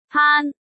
paan1.mp3